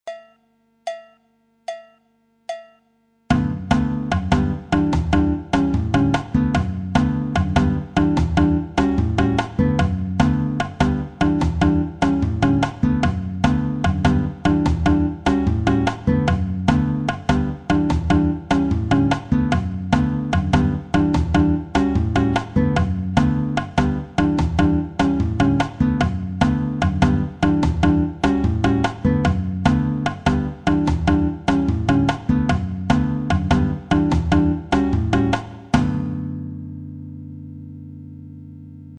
La bossa nova figure 4